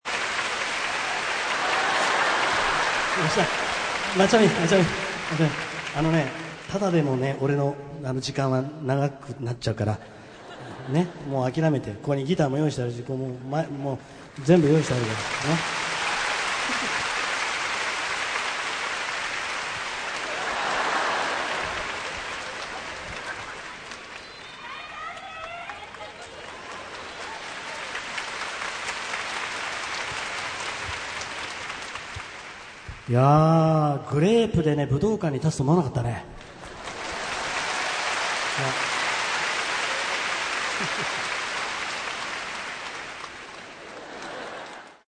ライブアルバム
2005年9月6・7日　日本武道館にて収録